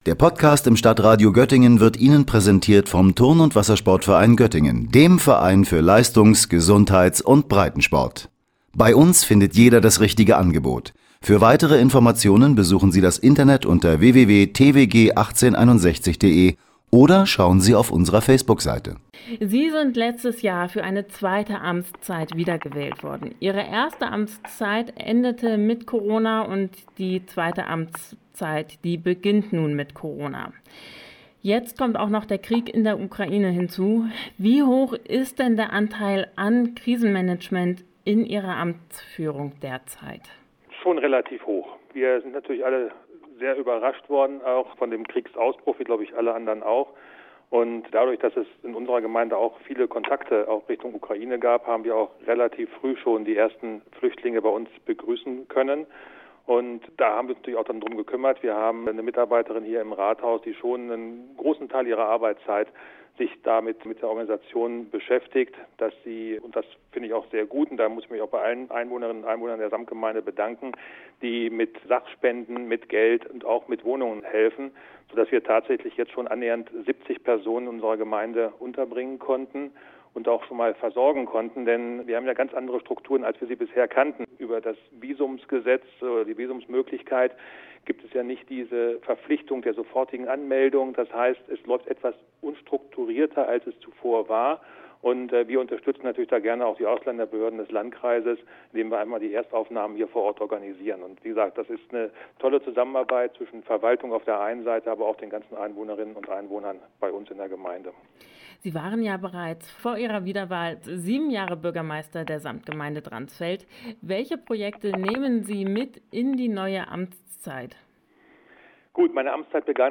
Dransfelds Samtgemeindebürgermeister Mathias Eilers im Interview